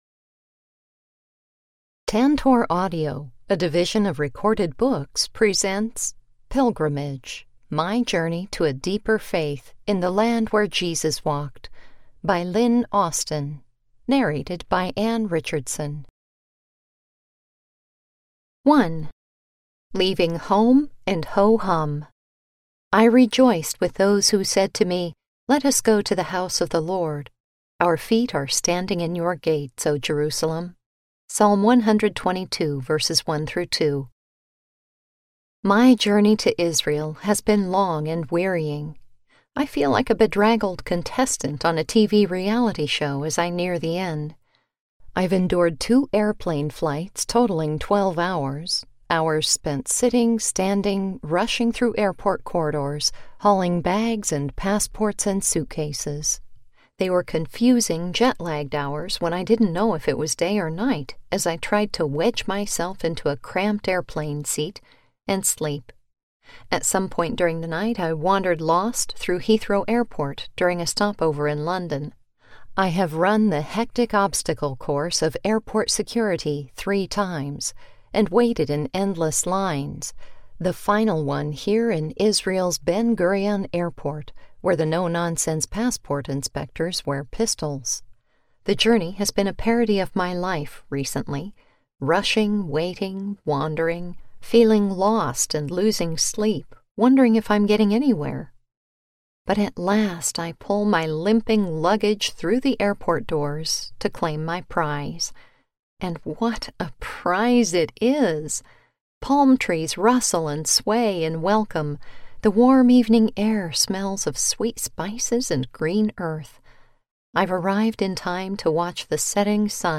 Pilgrimage Audiobook
Narrator
7.5 Hrs. – Unabridged